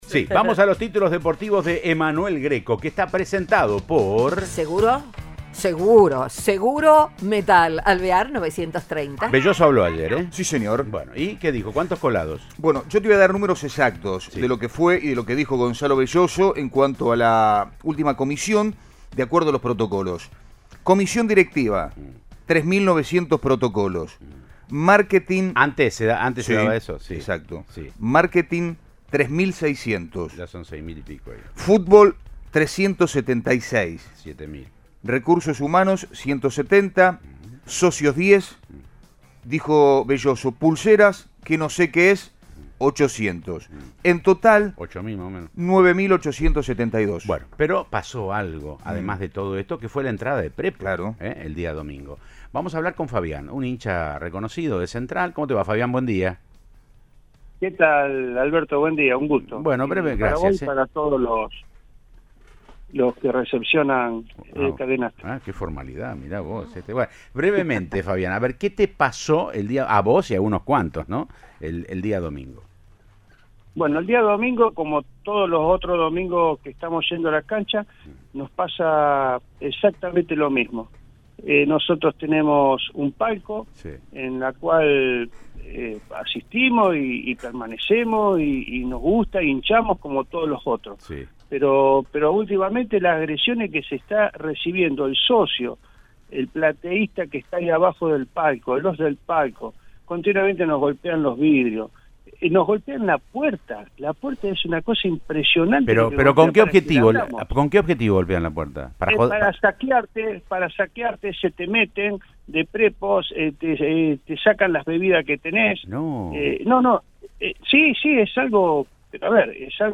Un simpatizante de Rosario Central contó las desagradables situaciones que tienen que vivir cada vez que va a la platea del estadio de Barrio Arroyito.